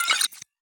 Hi Tech Alert 13.wav